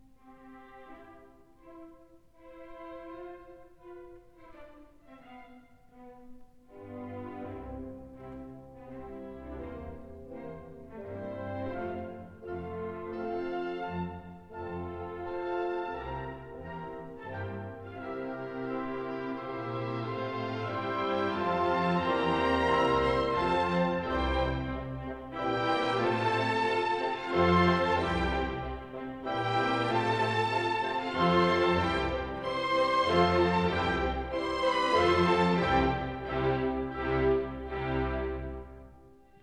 in C major, Op. 21